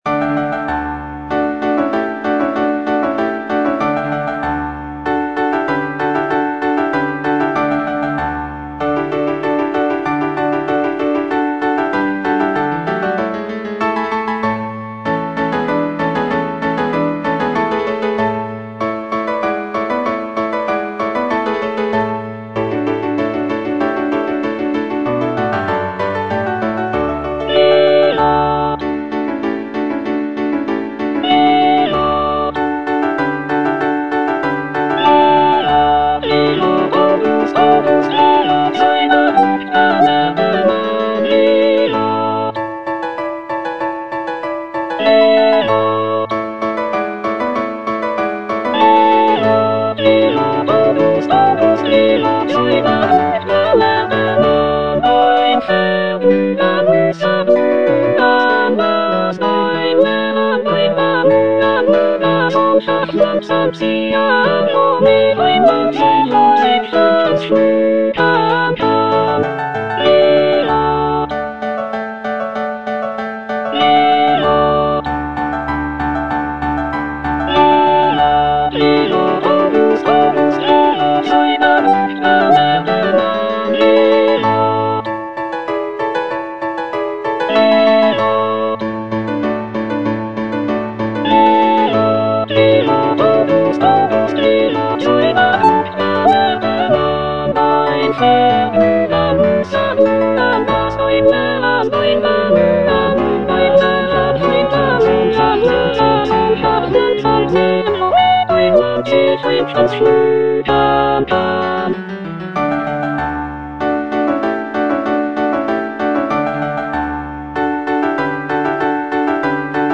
The cantata features a dialogue between the wind god Aeolus and the river god Alpheus, celebrating the prince's virtues and rulership. The music is lively and celebratory, with intricate counterpoint and virtuosic vocal lines.